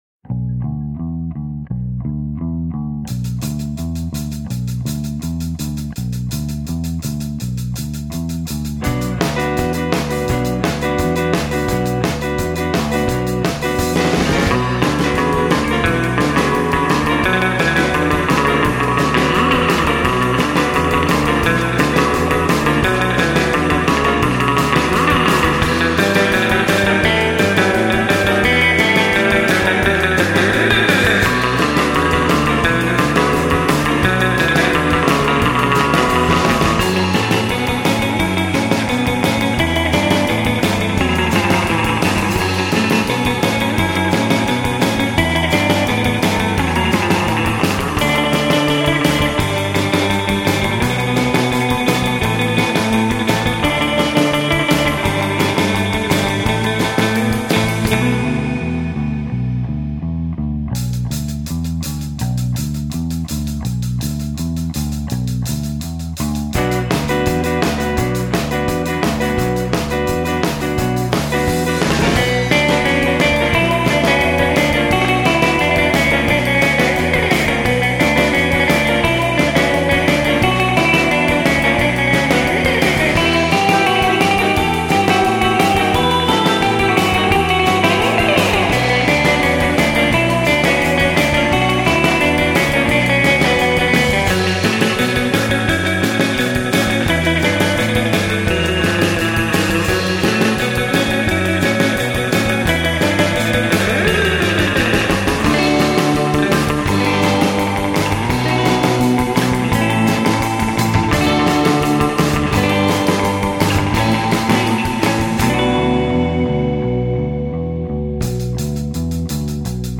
kitara
bobni